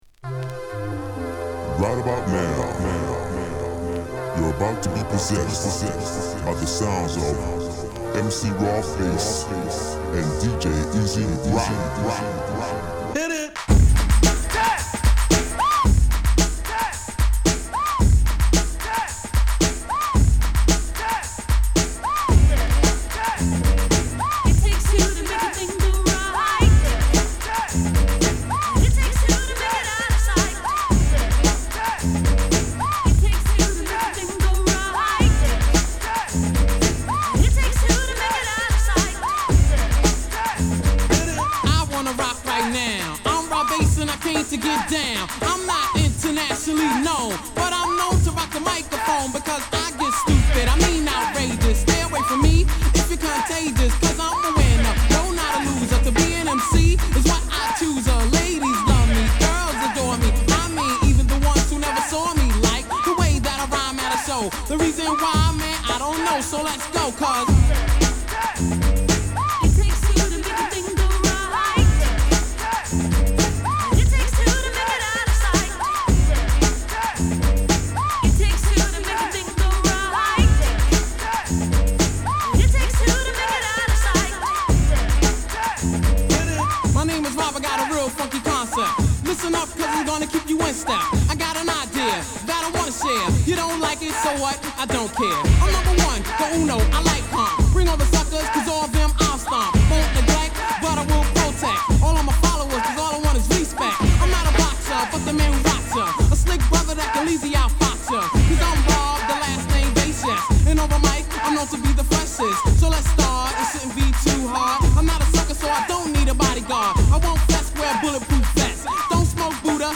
＊チリパチ出ます。